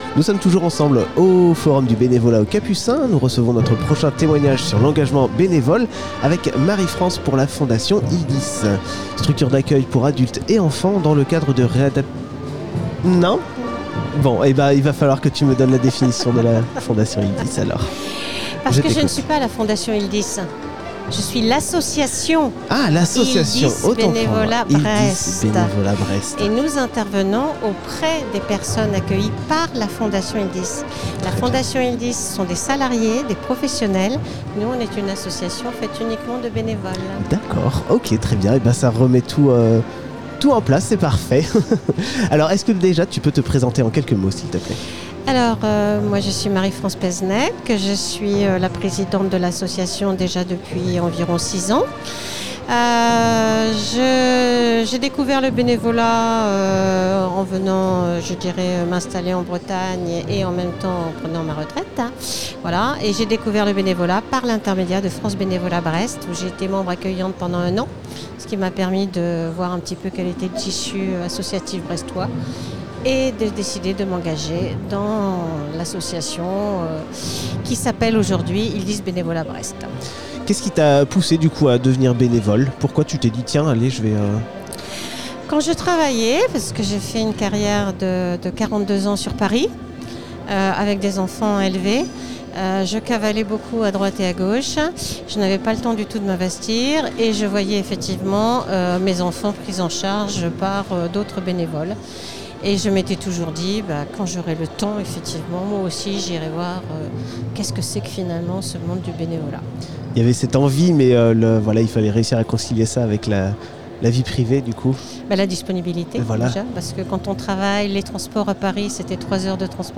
Samedi 1er Octobre 2022 se tenait aux Capucins les Rendez-Vous du Monde Associatif organisé par Brest’Assos.
Radio U était présente pour donner la parole aux différents acteurs et actrices du monde associatif sur Brest.
Nous recevions à notre micro des représentant·e·s salarié·e·s ou bénévoles des structures suivantes :